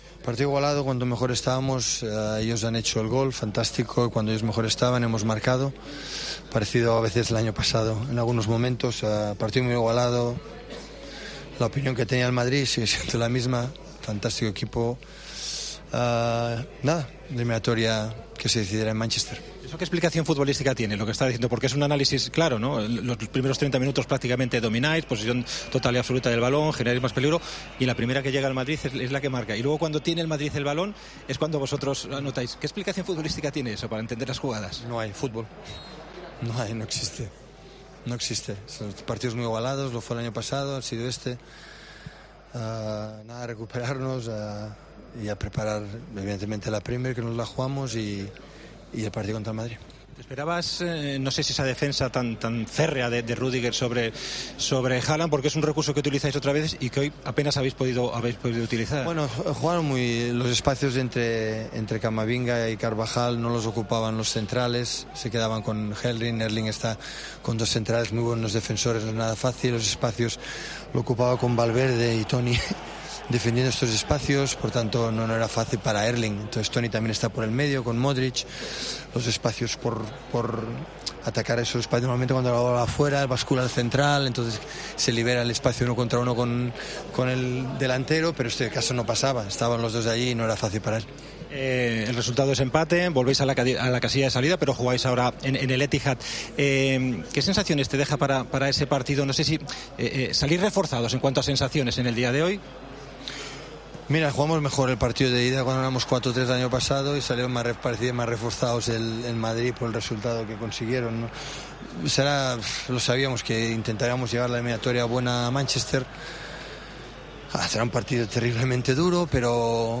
Pep Guardiola, entrenador del Manchester City, aseguró este martes en rueda de prensa que la "calidad" no se demuestra "marcando goles", alabó el partido que completaron sus jugadores ante el Real Madrid (1-1) y destacó la actuación de Antonio Rüdiger y de David Alaba, que secó por completo a Erling Haaland.